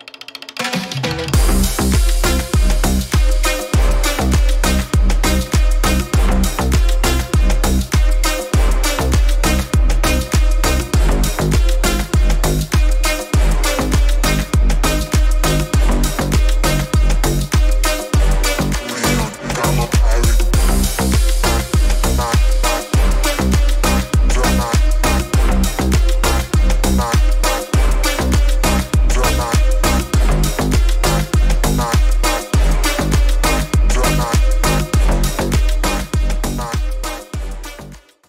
громкие
мощные
Electronic
EDM
без слов
Стиль: slap house